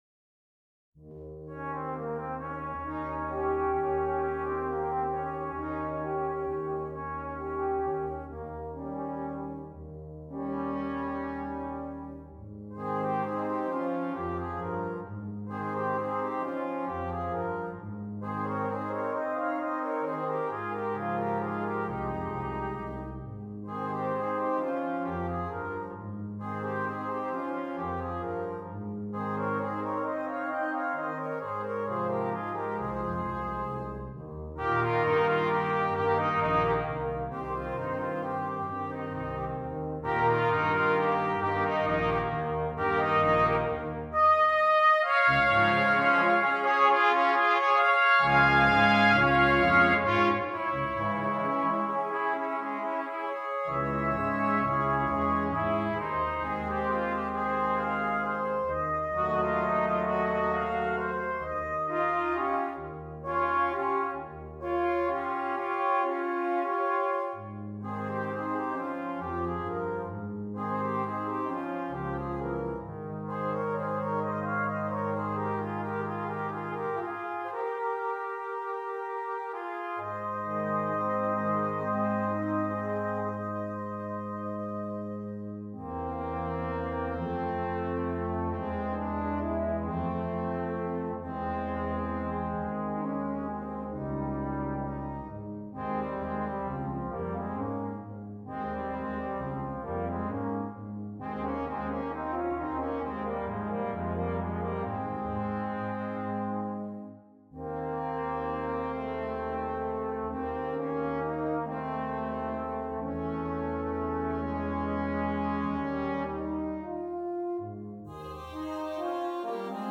Brass Quintet
This has been altered to 3/4 for ease of reading.